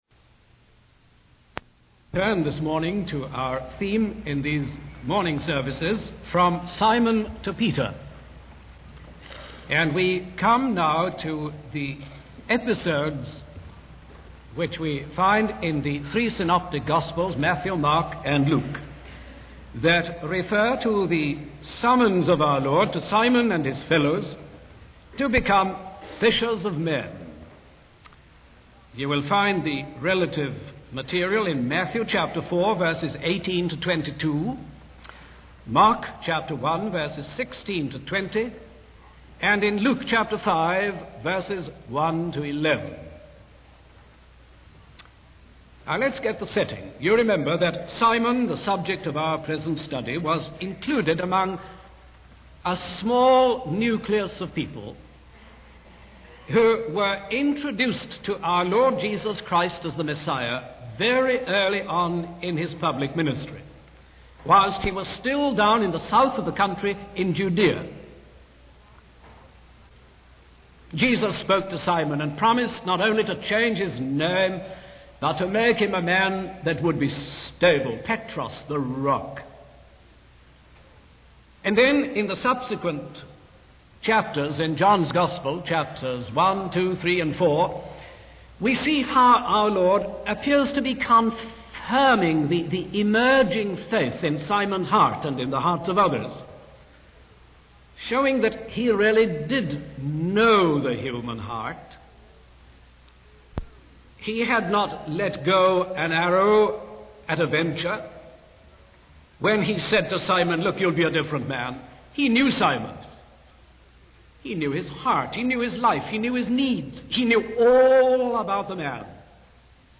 In this sermon, the speaker discusses the theme of becoming fishers of men, as mentioned in the Bible. The speaker highlights three main points: the task to which Simon and his followers were called, the training involved, and the token of success.